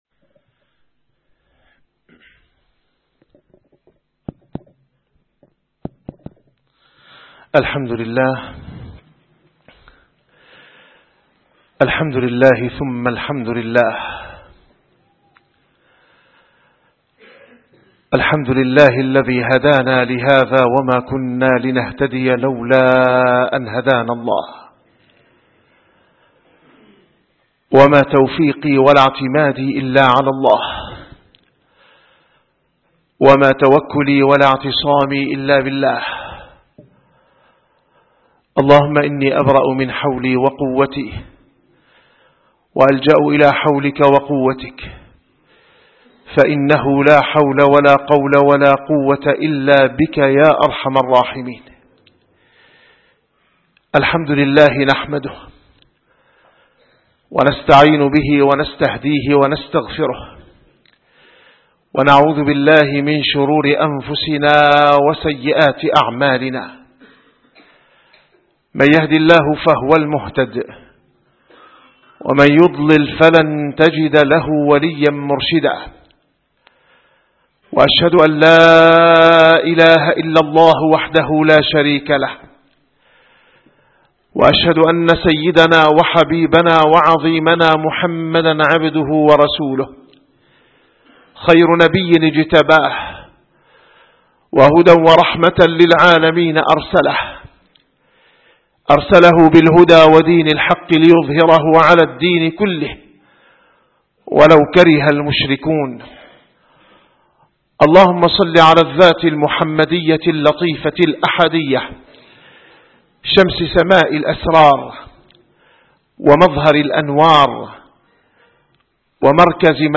- الخطب - خصائص الأنبياء تخرج من مشكاة واحدة والدليل بيان الله تعالى